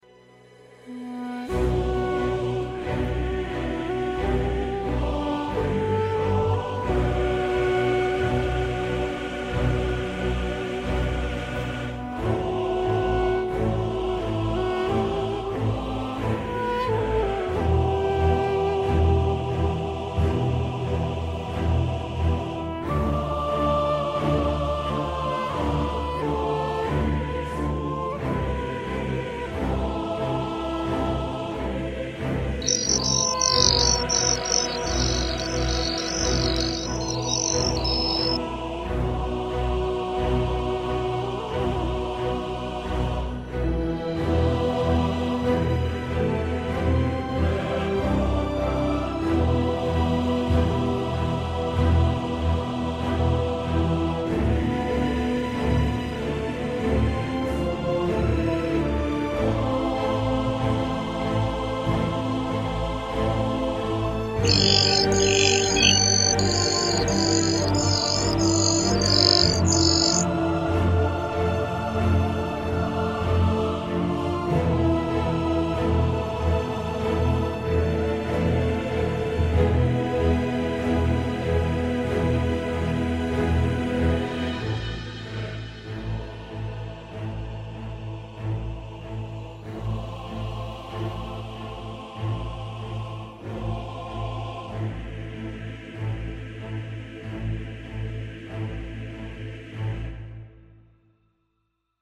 Echoes of triumph and defeat resonate through the arena as Dota 2 heroes unleash their powerful voices, each phrase a testament to their unique character and the epic battles they endure.